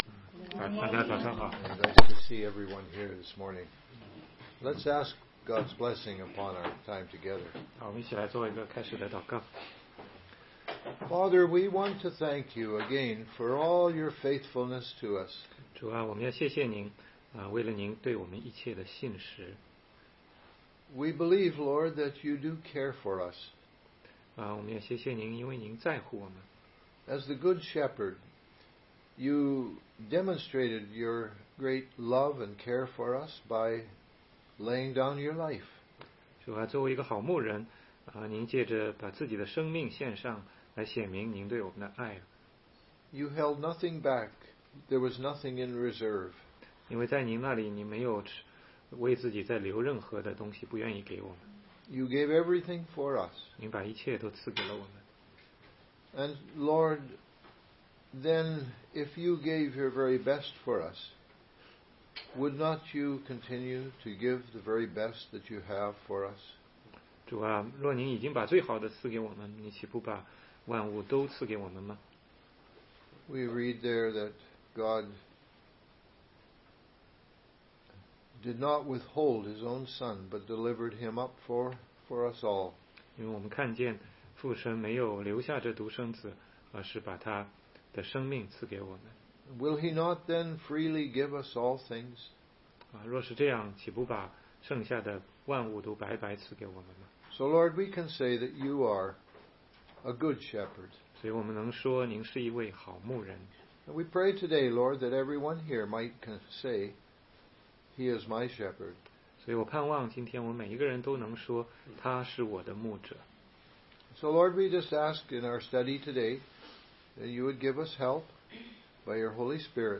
福音教会查经 - 约翰福音10章1-16节